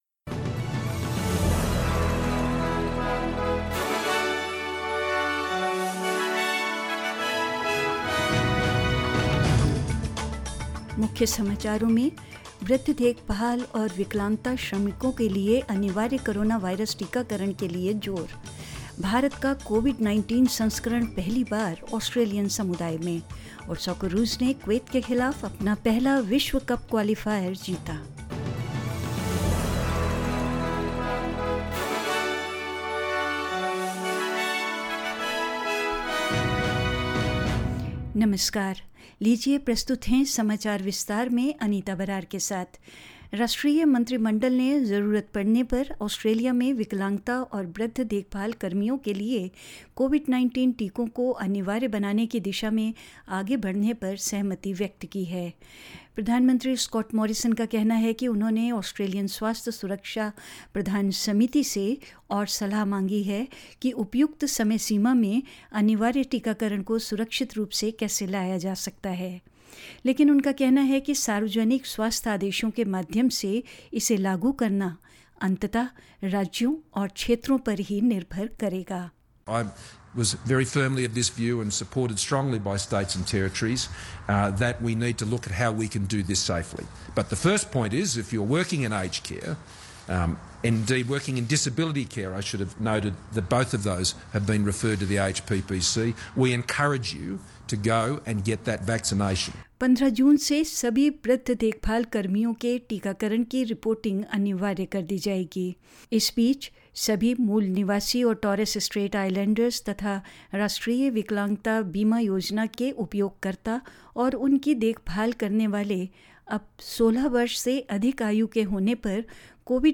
In this latest SBS Hindi News bulletin of Australia and India: Victoria recorded four new cases of locally acquired COVID-19, A COVID-19 variant dominant in India detected for the first time in the Australian community, India's COVID-19 graph continues to show a declining trend, and in sport, the Socceroos win their first World Cup qualifier against Kuwait and more news.